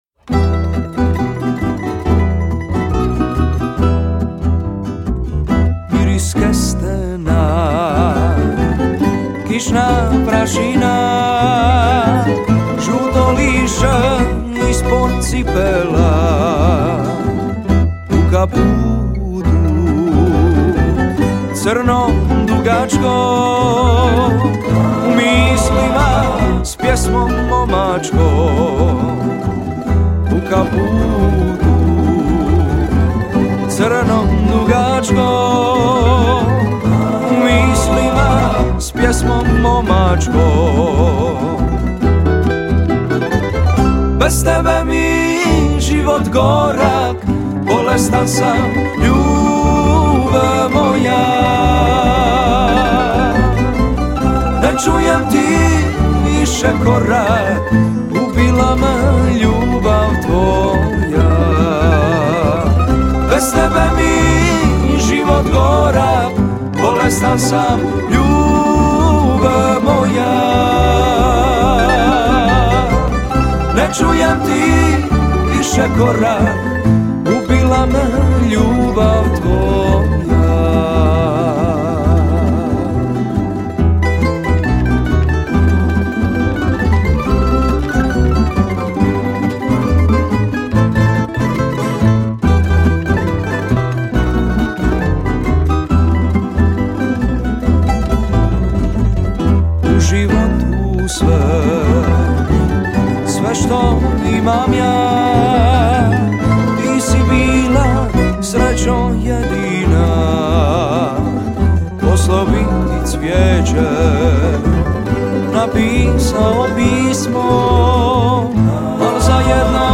37. Festival pjevača amatera
Zvuci tamburice do kasnih noćnih sati odzvanjali su prepunom dvoranom vatrogasnog doma u Kaptolu.
13. TS "ARTEŠKI BUNAR" -